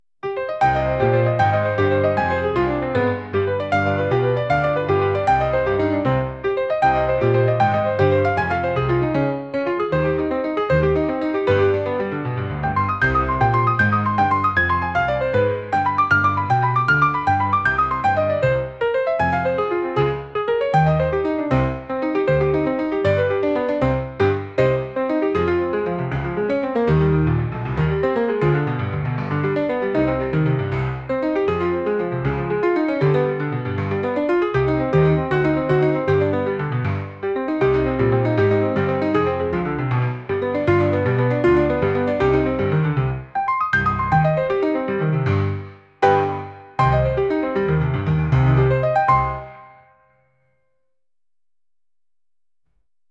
Sektion Echore) - wariacja na temat słowa "echo", jako dźwięk odbity, przetworzony – subtelny symbol muzyki kreowanej przez sztuczną inteligencję.
Mam przyjemność przedstawić utwór w kategorii Echore, który wykonywany jest przez znamienitego sarmackiego pianistę.
Wiadomo, generowane granie, ale nadal brzmi całkiem sprawnie, jak na warunki mikronacyjne.